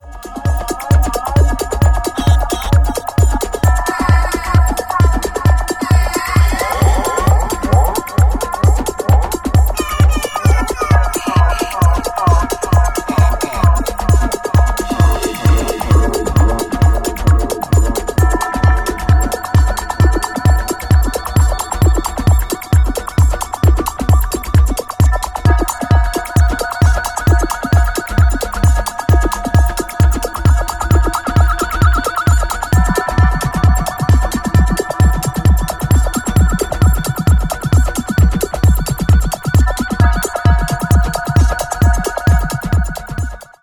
Sequencer Demos
Note that the parts are mostly played live within one evening regardless of the sound quality.
Very experimental (the original is about 3 hours long, but I forgot to record it ;-), many Fx have been added, some of them are controlled from the sequencer via CCs. all patterns made with MIDIbox SEQ and recorded with Logic whenether they worked (means: the MIDIbox SEQ didn't play the whole song in this example since the song mode was not implemented at this time)